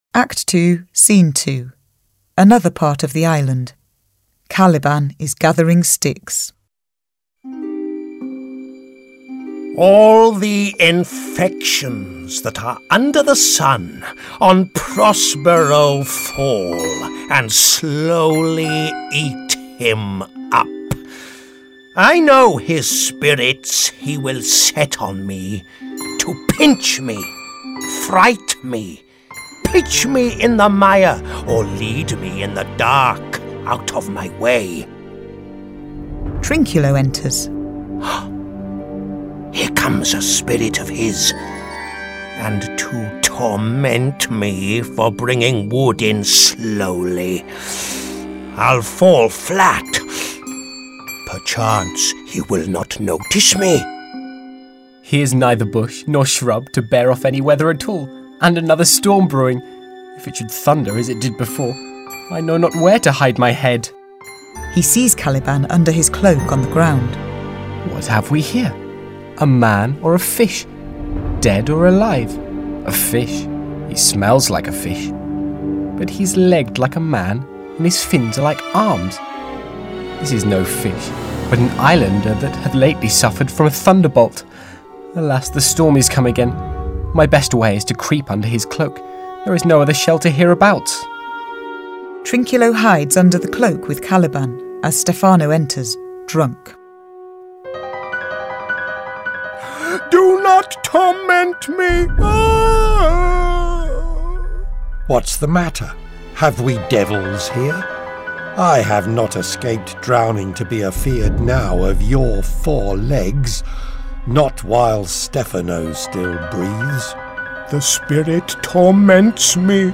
附贈英語朗讀故事CD，搭配情境音效更生動